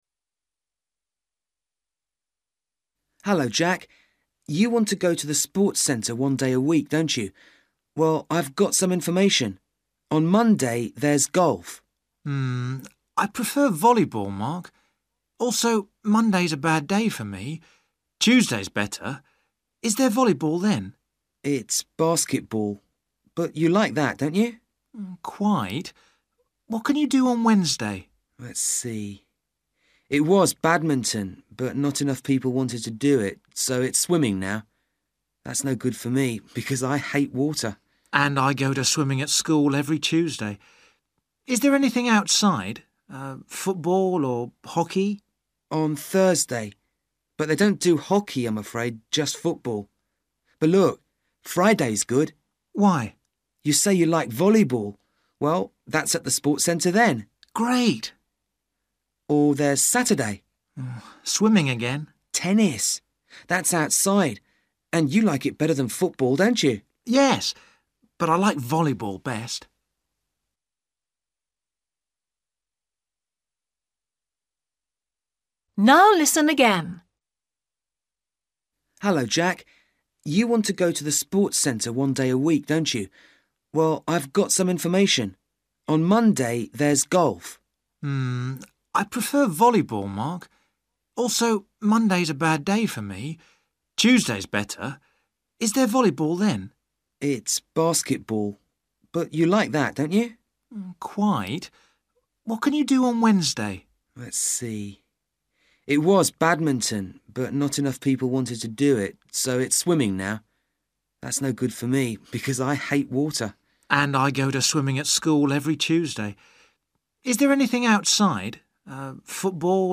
You will hear the conversation twice.